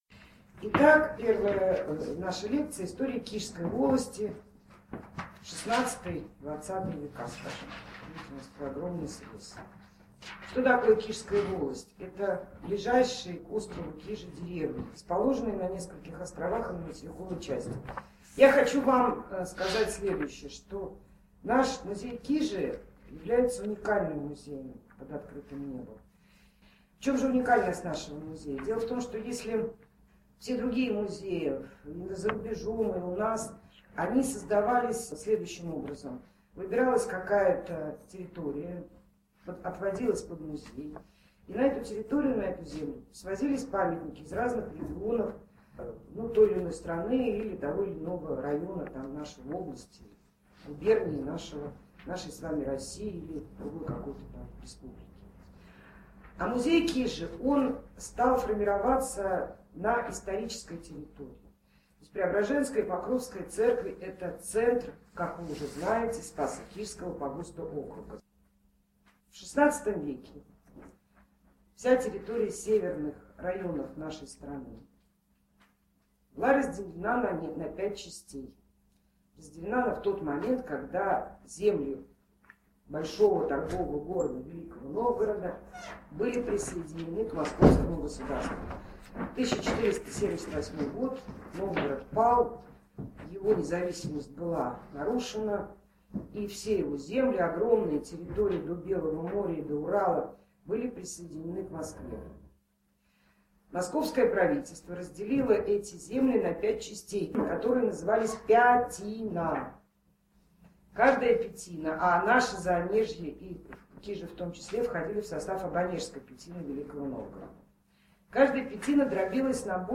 Метки текста: Методический кабинет Смотри также: [АУДИО] Крестьянская община (аудиозапись лекции, mp3) [АУДИО] Кижская волость (аудиозапись лекции, mp3) 4.
kizhskaya_volost_audiozapis_lektsii_1.mp3